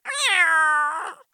cat_meow_normal2.ogg